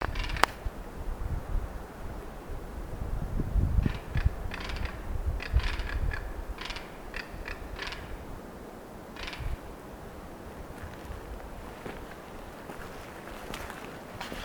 Carancho (Caracara plancus)
Fase de la vida: Adulto
Localidad o área protegida: Parque Nacional Nahuel Huapi
Condición: Silvestre
Certeza: Observada, Vocalización Grabada